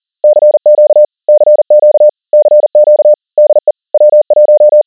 CW (Morse) Modes
CW 40 WPM
CW is generally transmitted using on-off or 100% amplitude keying, i.e. ASK modulation.
CW40 20 baud ~ 4 cps (40 wpm) ~ 44% 100 Hz 100HA1A
CW40wpm.wav